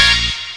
Gunit Synth16.wav